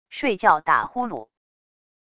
睡觉打呼噜wav音频